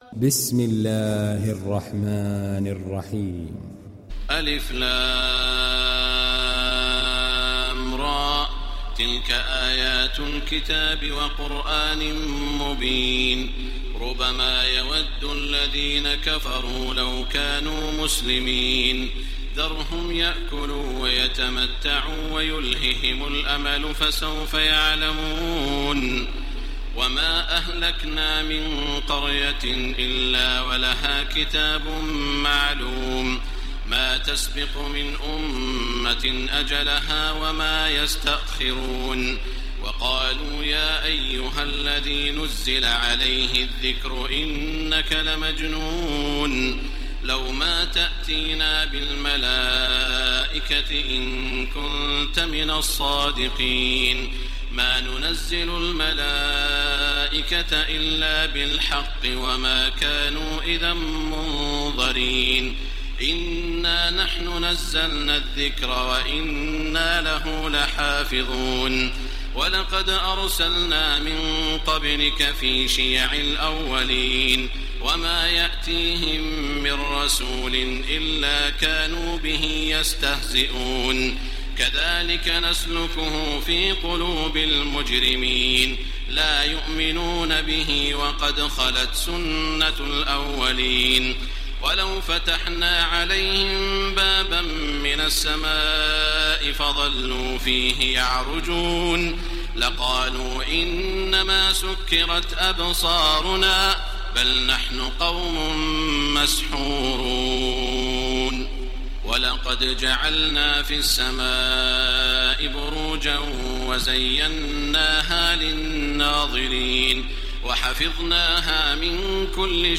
دانلود سوره الحجر mp3 تراويح الحرم المكي 1430 روایت حفص از عاصم, قرآن را دانلود کنید و گوش کن mp3 ، لینک مستقیم کامل
دانلود سوره الحجر تراويح الحرم المكي 1430